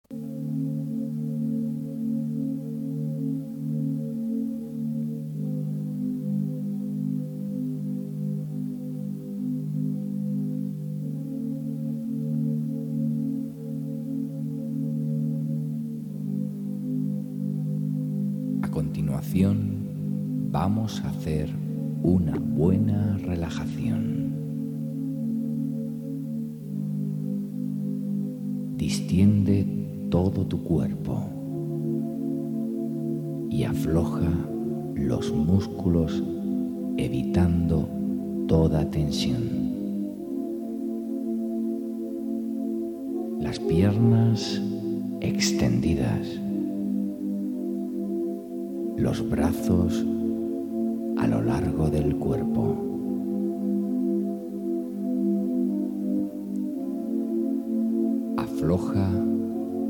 Si se usan con un reproductor de sonido, es conveniente oírlos usando auriculares, ya que existen ligeras diferencias entre la frecuencia que percibe un oído y el otro, para aumentar su eficacia y esto se pierde si se escuchan a través de altavoces.